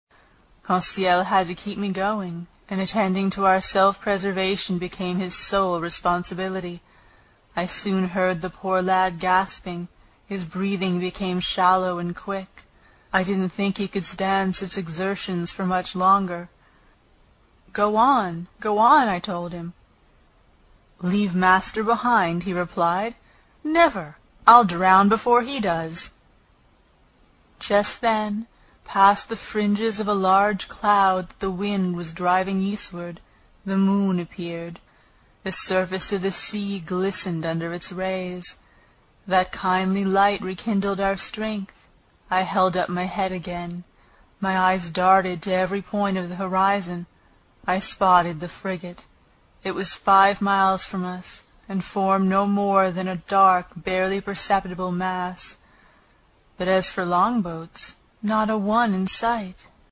英语听书《海底两万里》第84期 第7章 一种从未见过的鱼(7) 听力文件下载—在线英语听力室
在线英语听力室英语听书《海底两万里》第84期 第7章 一种从未见过的鱼(7)的听力文件下载,《海底两万里》中英双语有声读物附MP3下载